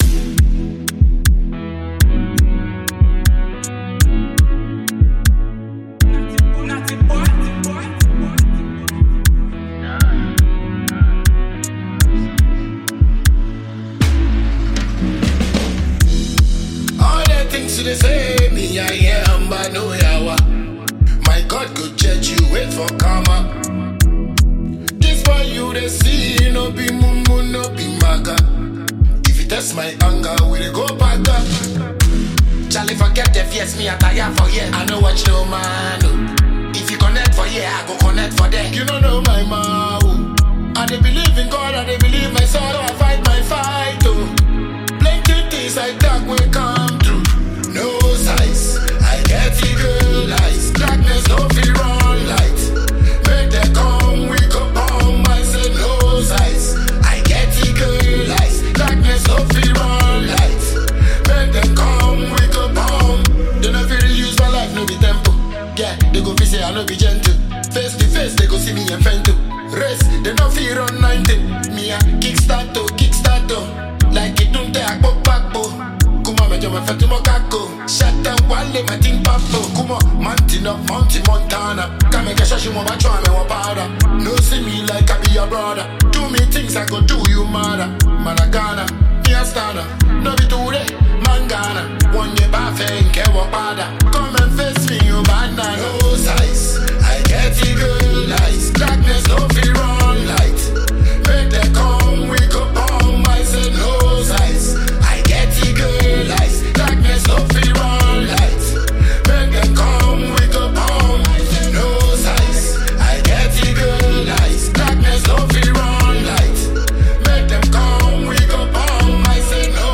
the African Dancehall King from Ghana